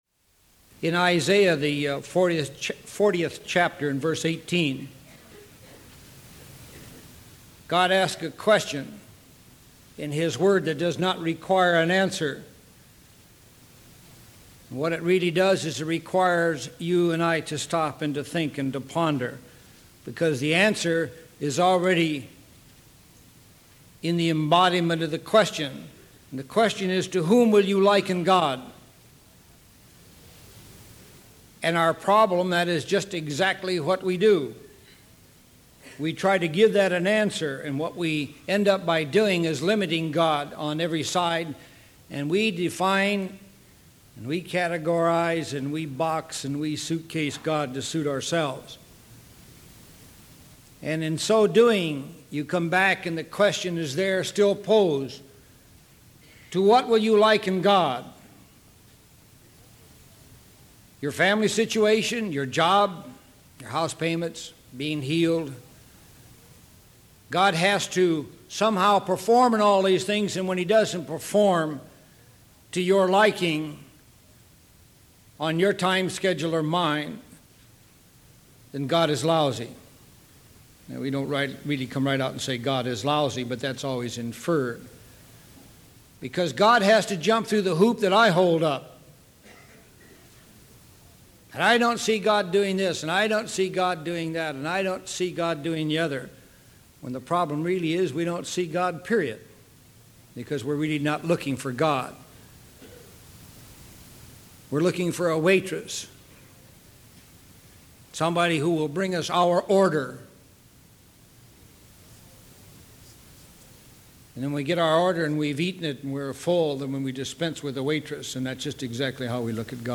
God's Way download sermon mp3 download sermon notes Welcome to Calvary Chapel Knoxville!